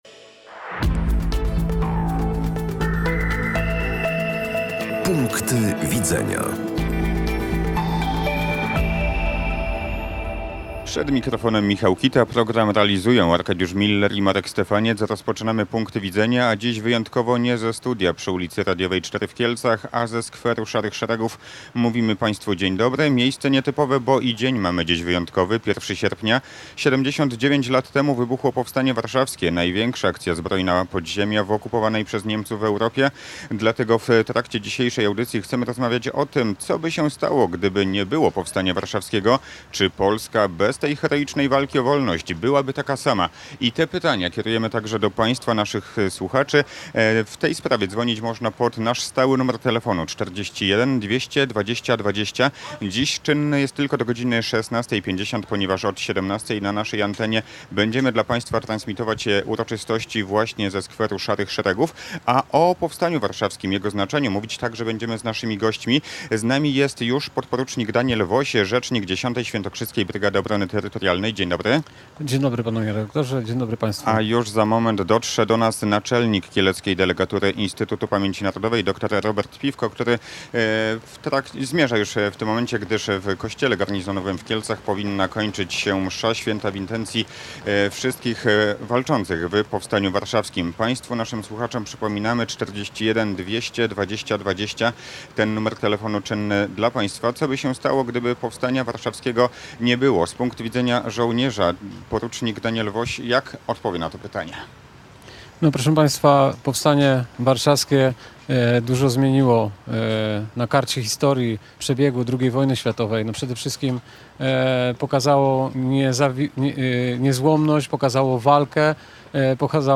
– 1 sierpnia 1944 roku to data, wpisana w kanon polskiej tożsamości. Nie tylko mieszkańca Warszawy – stwierdził w Raporcie Parlamentarnym na antenie Radia Kielce senator Jarosław Rusiecki.